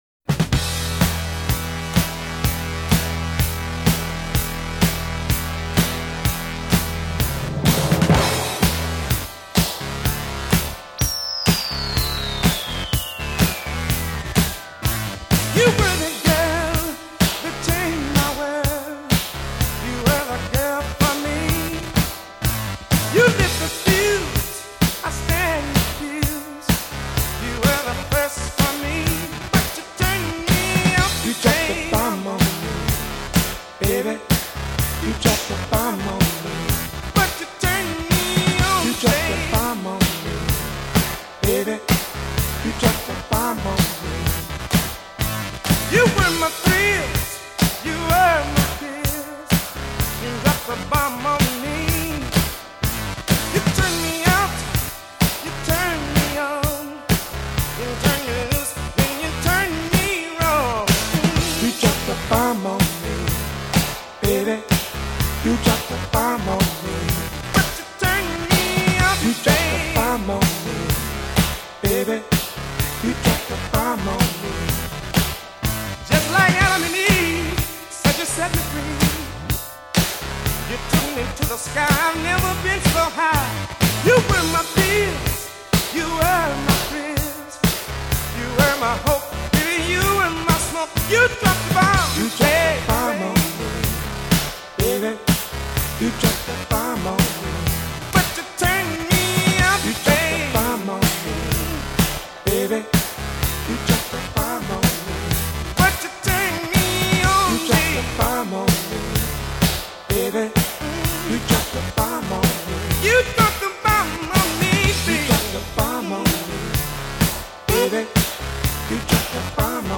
Tag: electro
This is classic example of merging funk with electro.